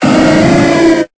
Cri de Noarfang dans Pokémon Épée et Bouclier.